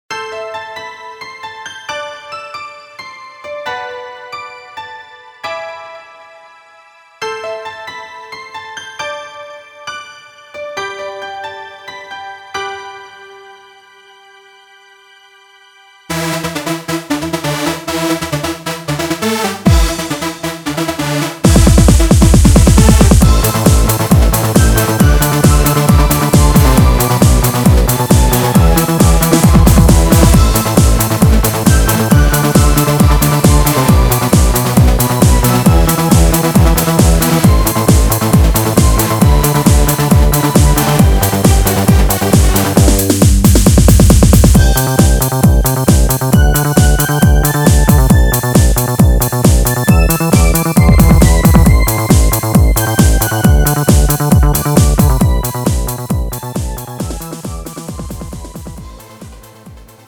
음정 원키 3:34
장르 가요 구분 Pro MR